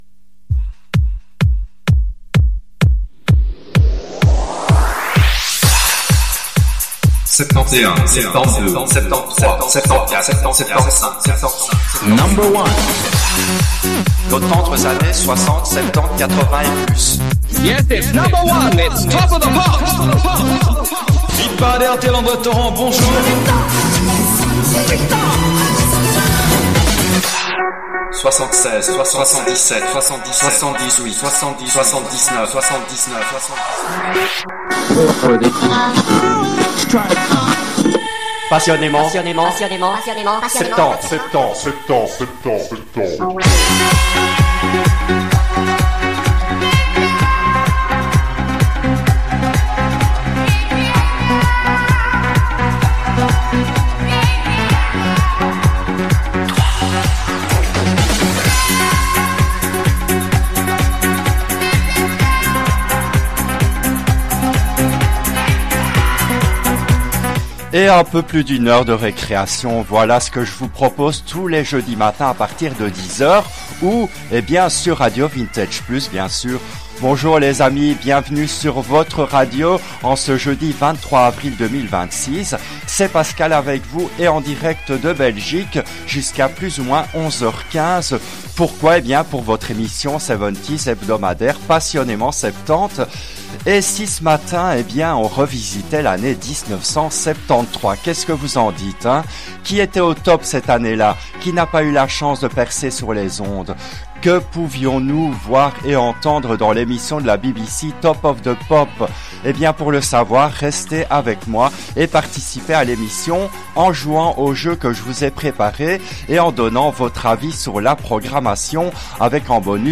L’émission a été diffusée en direct le jeudi 23 avril 2026 à 10h depuis les studios belges de RADIO RV+.